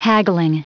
Prononciation du mot haggling en anglais (fichier audio)
Prononciation du mot : haggling